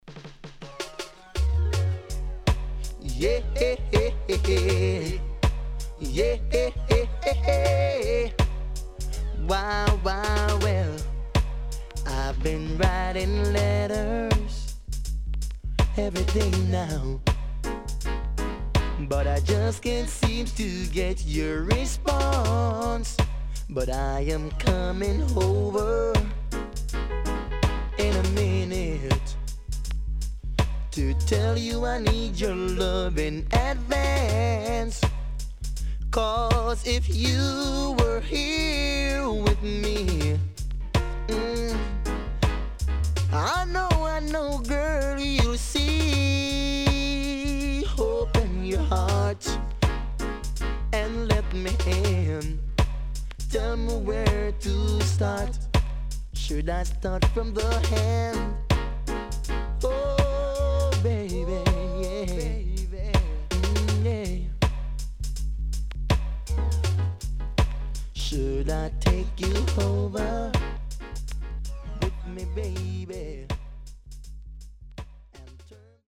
HOME > LP [DANCEHALL]
SIDE A:少しチリノイズ入りますが良好です。盤面は所々うすいこまかい傷あり。